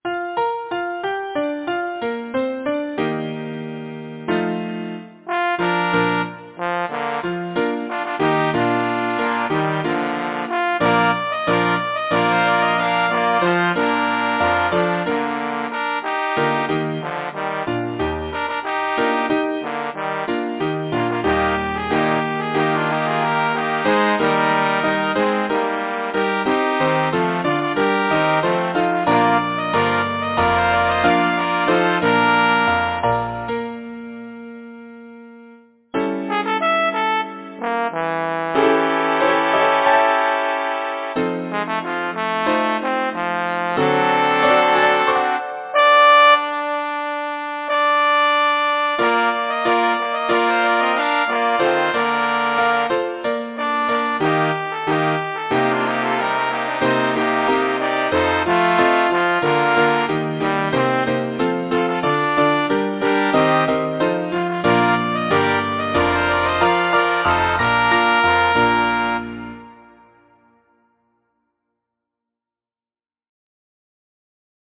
Title: The Old Person of Cassel Composer: Margaret Ruthven Lang Lyricist: Edward Lear Number of voices: 4vv Voicing: SATB Genre: Secular, Partsong
Language: English Instruments: Piano
First published: 1909 Arthur P. Schmidt Description: Arranged for mixed voices by composer from: Nonsense Rhymes and Pictures by Edward Lear, Op. 42